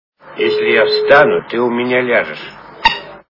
» Звуки » Из фильмов и телепередач » Операция Ы и другие приключения Шурика - Если я встану ты у меня ляжешь
При прослушивании Операция Ы и другие приключения Шурика - Если я встану ты у меня ляжешь качество понижено и присутствуют гудки.